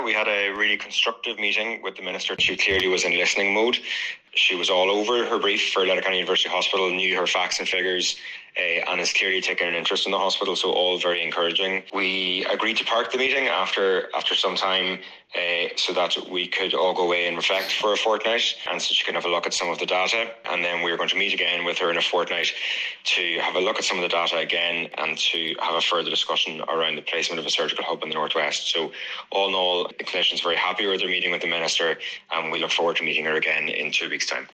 Speaking after the hour long meeting